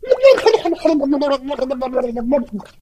otis_kill_vo_03.ogg